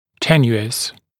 [‘tenjuəs][‘тэнйуэс]незначительный, слабый, тонкий